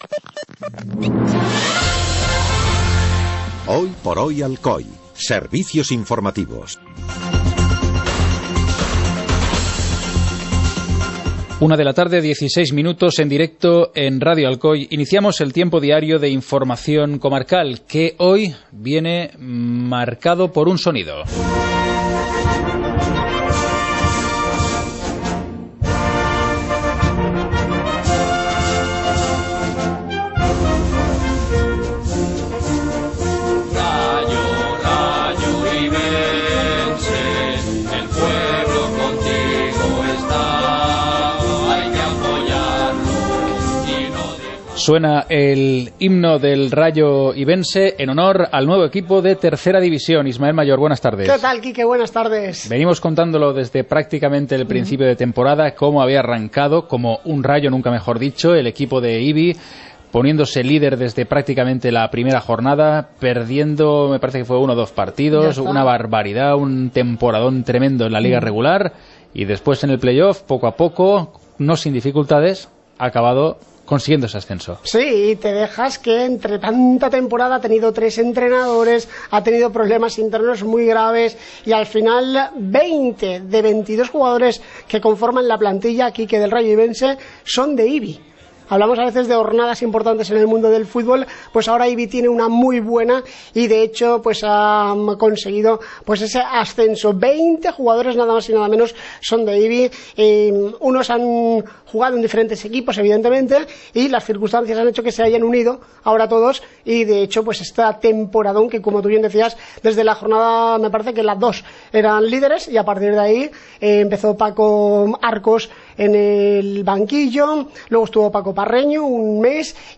Informativo comarcal - lunes, 22 de junio de 2015